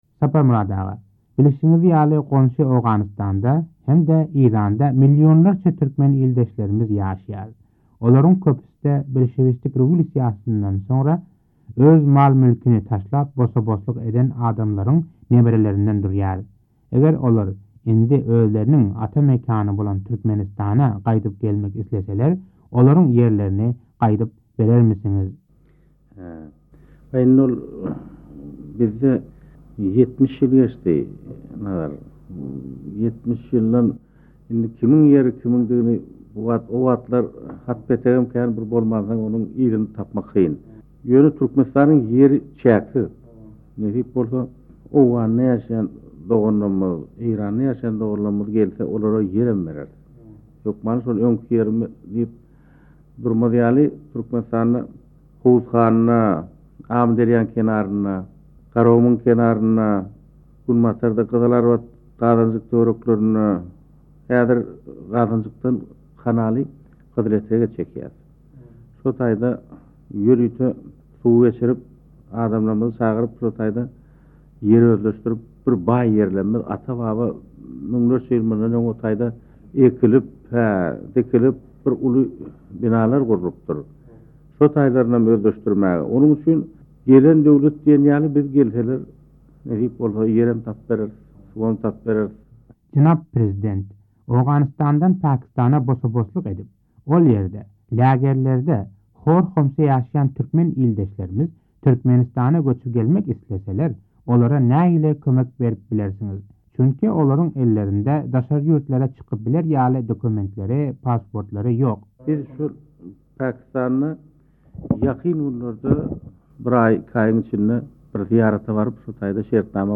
Azatlyk Radiosynyň Nyýazow bilen geçiren interwýusy